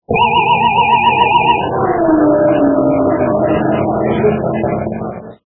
boxenklingel_24h.mp3